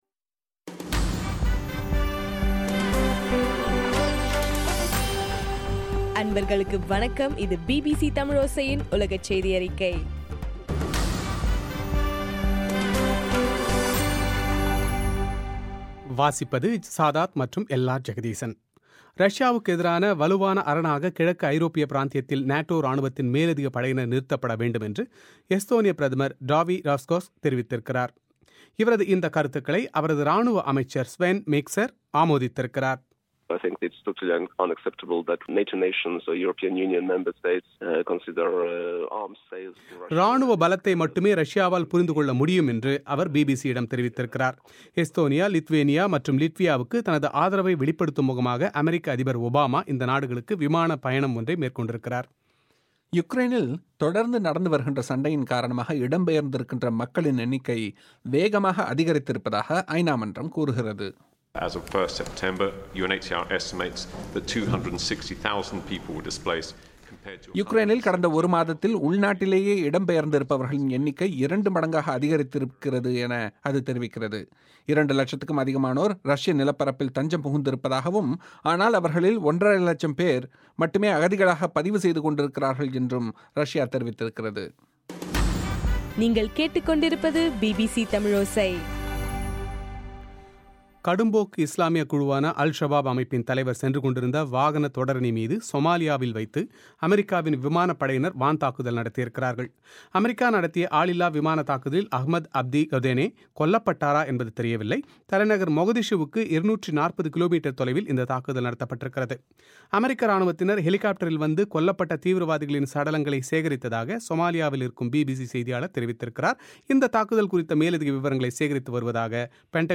செப்டம்பர் 2 பிபிசியின் உலகச் செய்திகள்